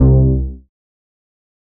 TM88 SYNTH BASS (2).wav